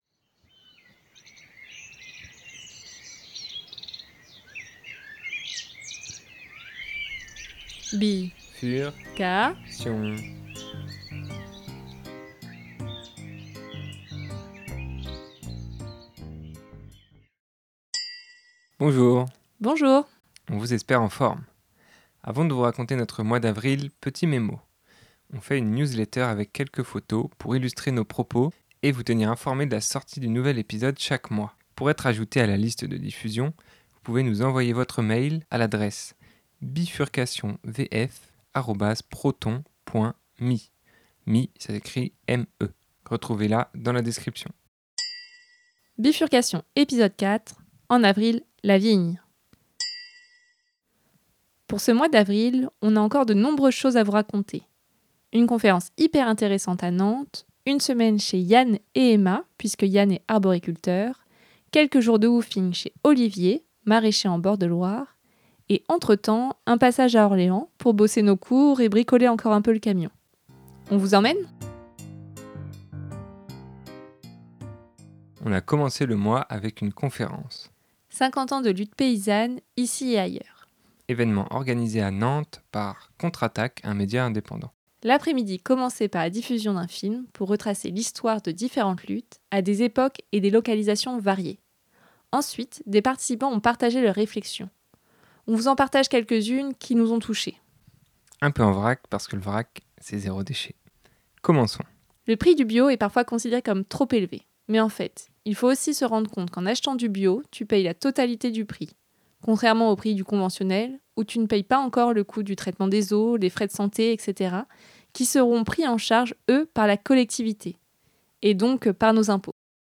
des interviews et un peu de nous !